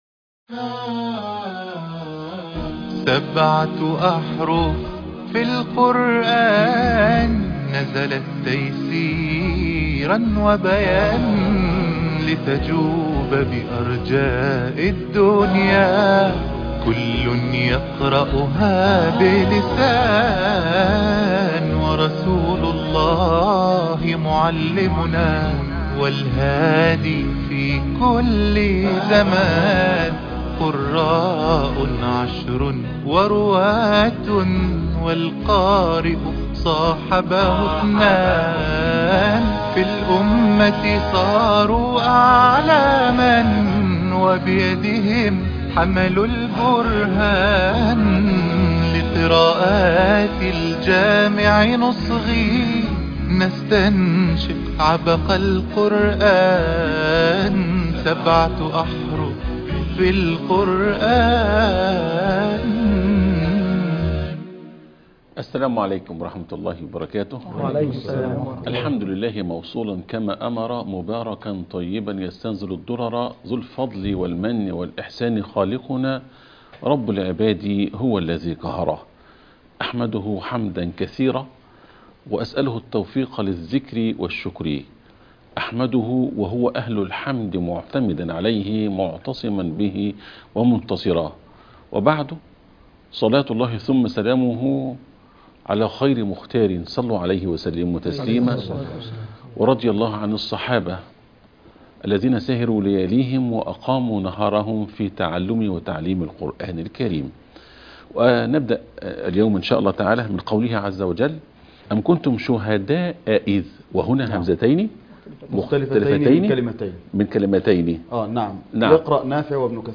الجامع للقراءات العشر سورة البقرة -19-